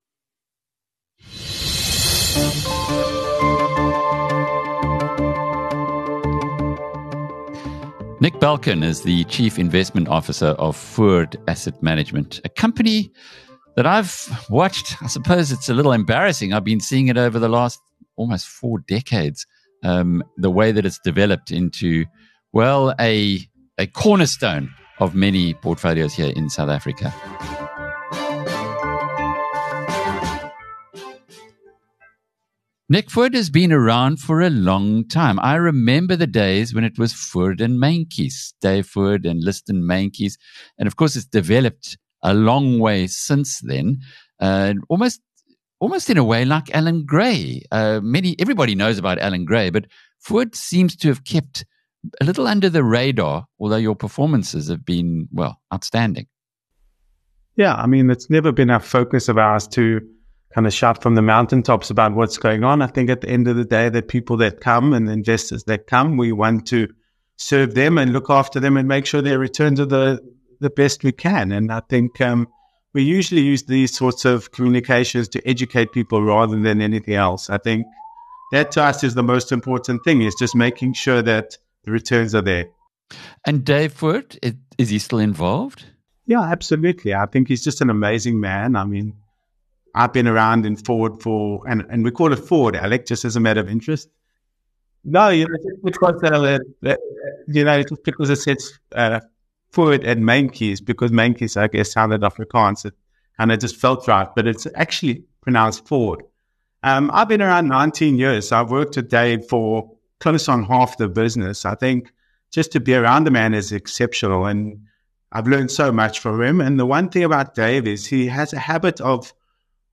In an engaging interview